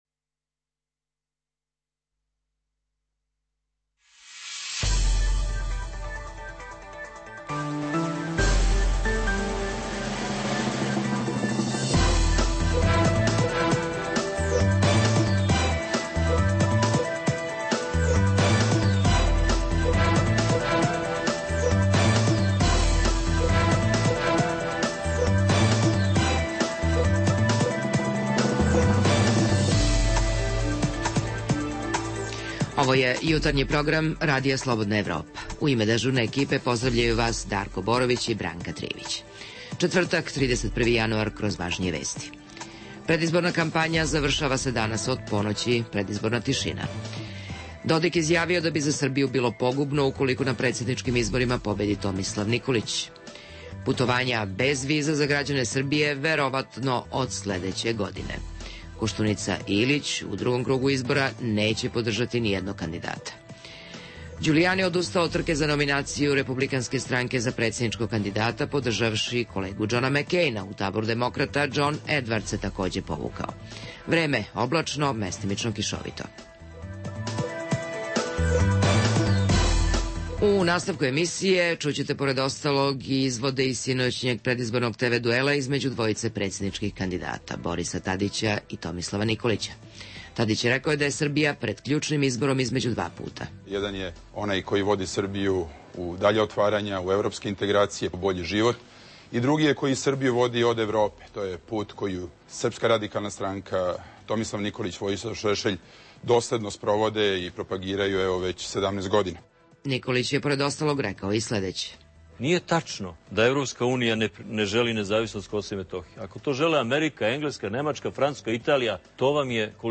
Emisija namenjena slušaocima u Srbiji. Sadrži lokalne, regionalne i vesti iz sveta, tematske priloge o aktuelnim dešavanjima iz oblasti politike, ekonomije i slično, te priče iz svakodnevnog života ljudi, kao i priloge iz sveta. Poslušajte i izvode iz predizbornog TV duela predsedničkih kandidata Tadića i Nikolića